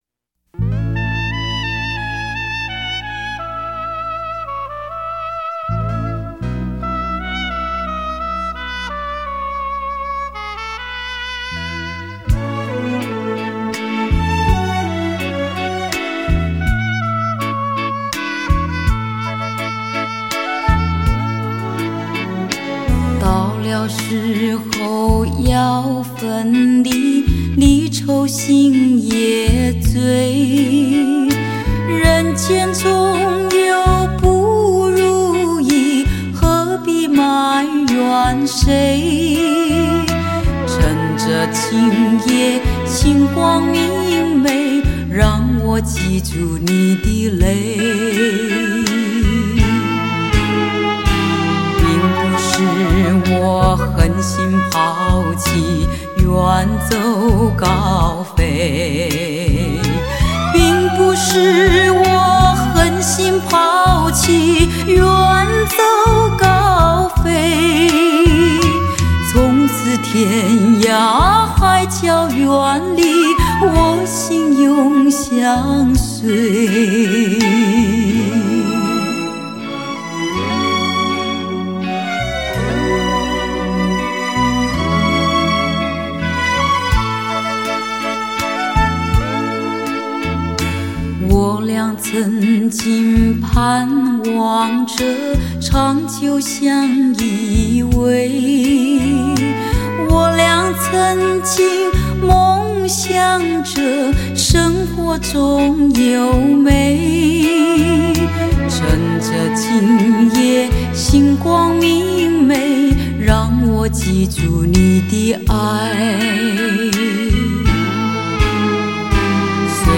尤其在名家的优美管弦乐编曲陪衬下，整张专辑依然飘散着一股优美而令人陶醉的迷人气氛